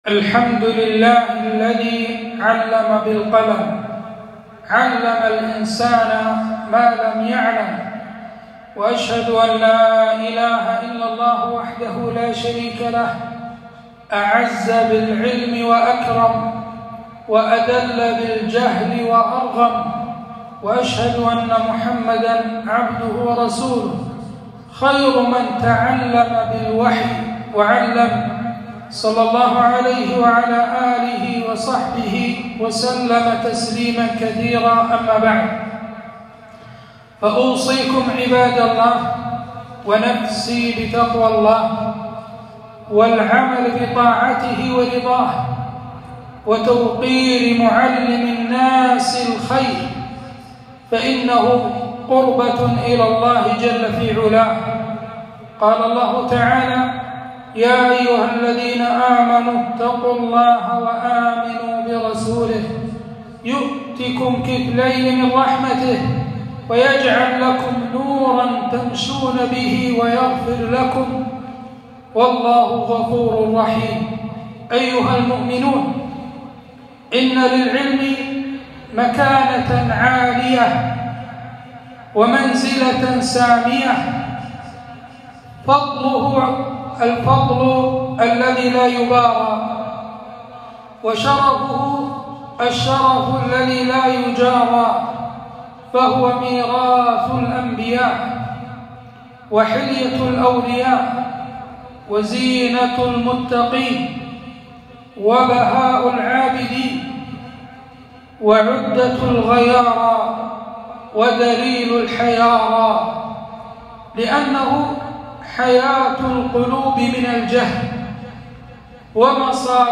خطبة - فضل العلم وشرف أهله